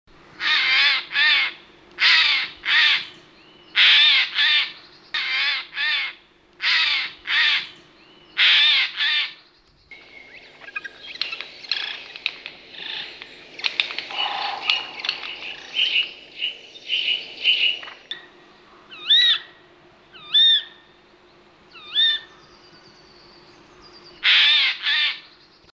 Sojka obecná (Garrulus glandarius)
Skřehotavý křik sojek v blízkosti hnízda.
sojka.mp3